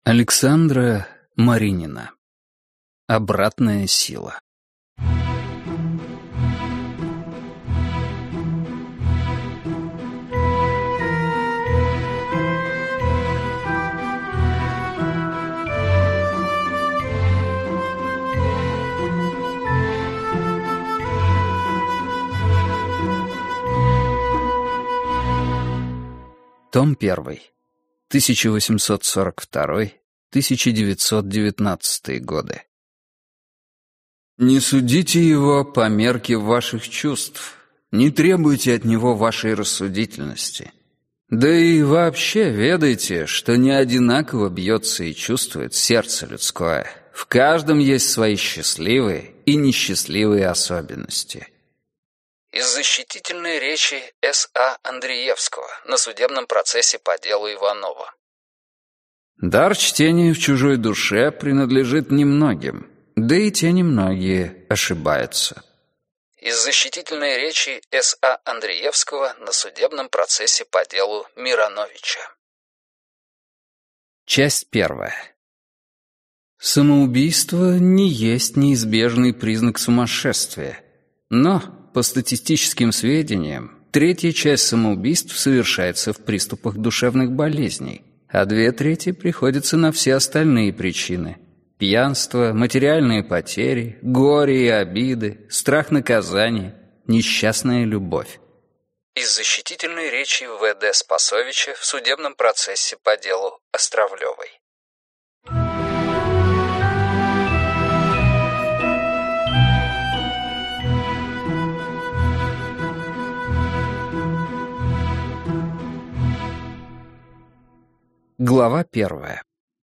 Аудиокнига Обратная сила.